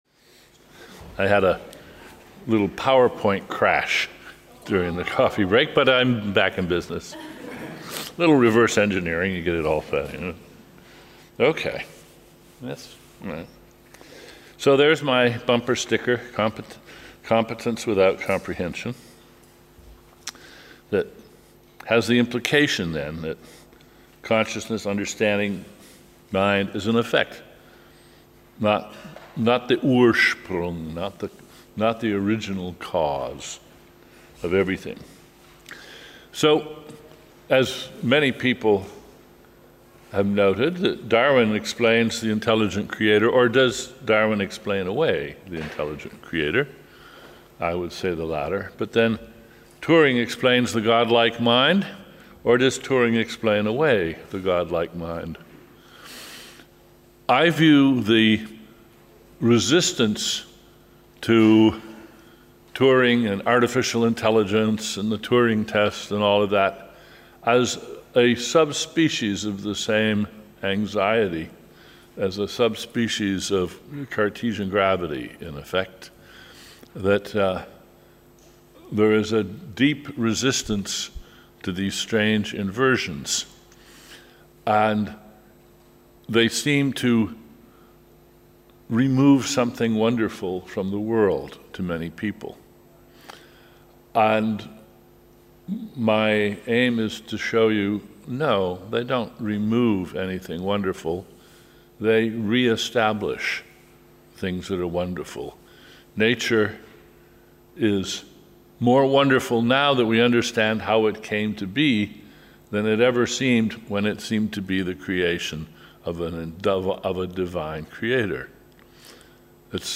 Quarta lliçó del seminari del professor i filòsof nord-americà, Daniel Clement Dennet, sobre filosofia de la ment